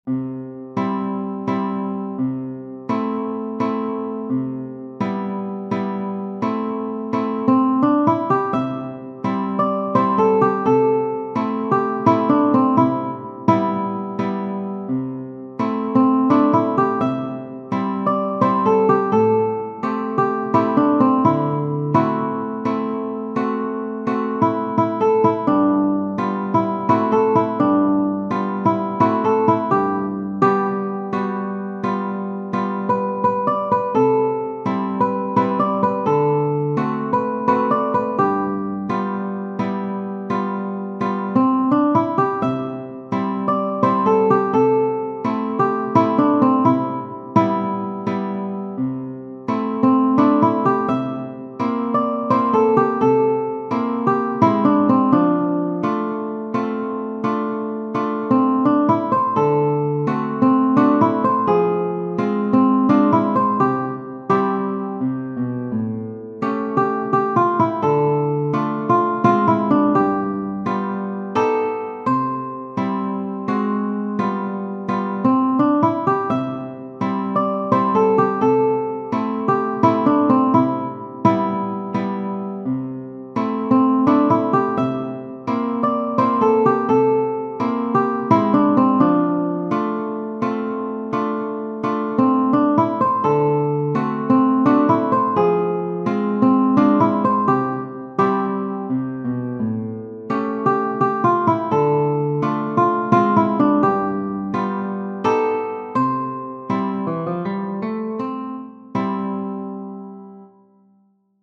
Genere: Moderne
è un valzer lento orchestrale